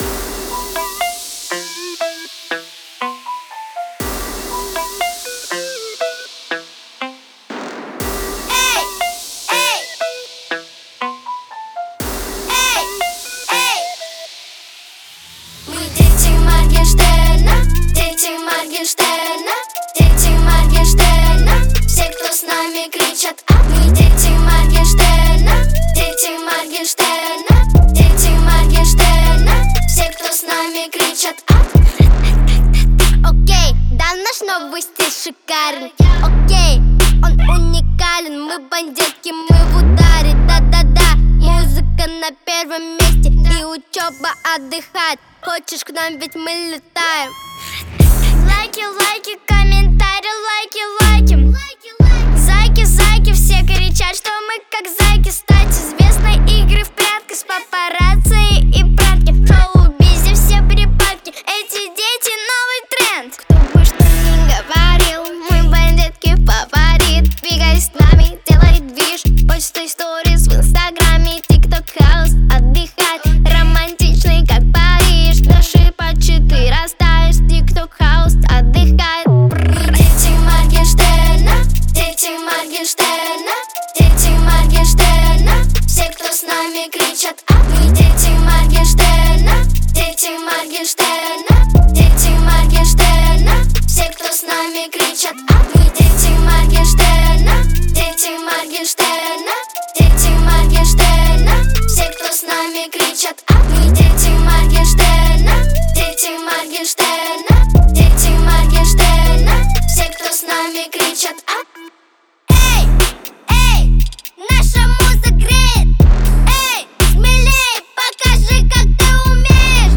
яркая и энергичная песня в жанре хип-хоп